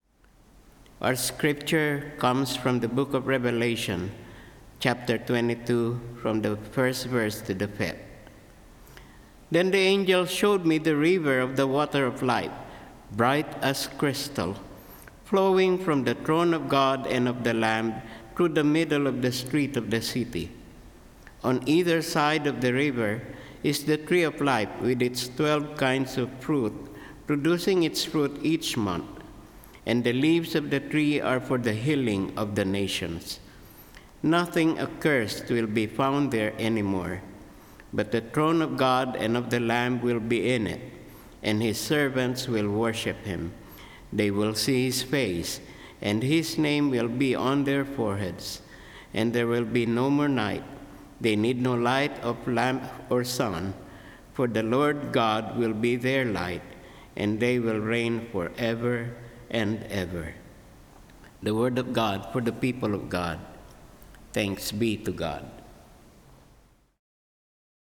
Service of Worship
Scripture Reading — Revelation 22:1-5 (NRSV)